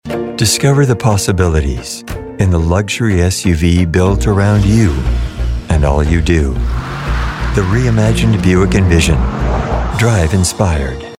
Buick Envision/ Confident, Sophisticated, Inspirational
Middle Aged